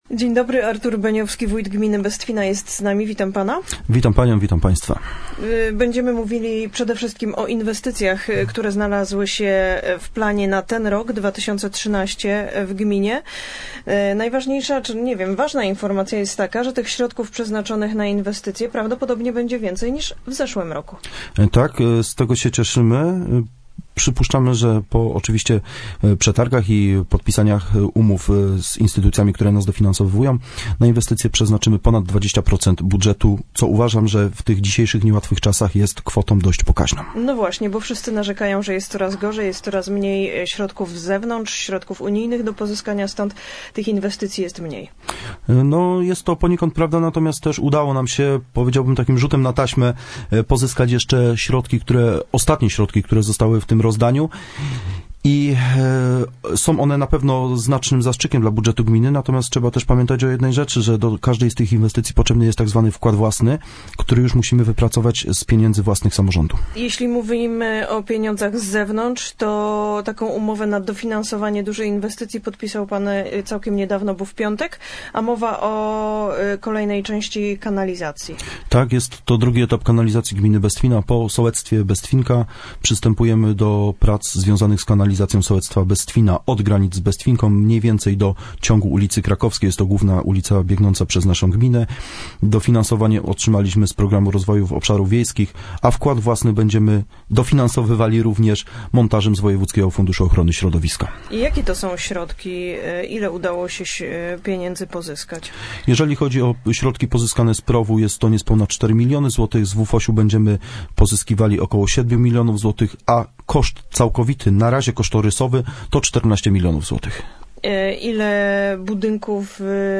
5 marca br. w Radio Bielsko gościł i udzielił wywiadu na temat inwestycji w gminie Bestwina wójt Artur Beniowski.